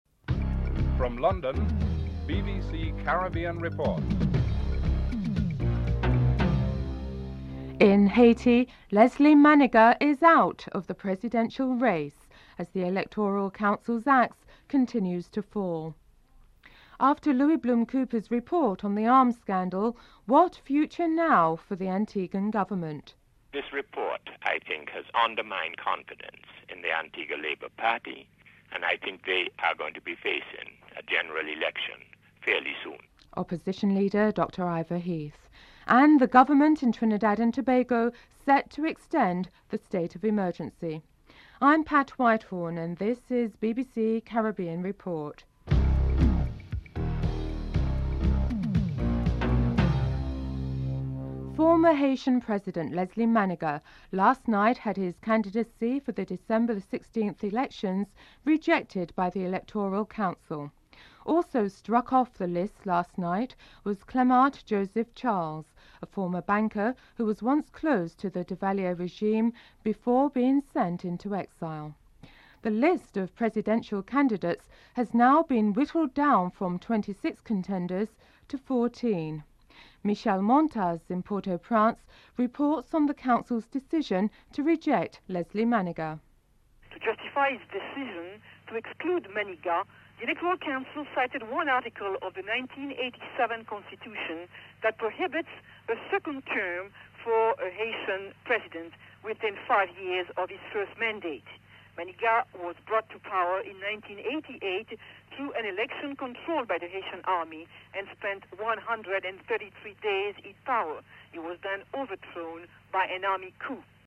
1. Headlines (00:00-00:51)
British Virgin Islands seize more than 600 kg of cocaine with a street value of approximately $60 million in the islands biggest drug haul. Interview with John Rutherford, Police Commissioner (10:35-12:48)